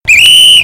Download Whistle sound effect for free.
Whistle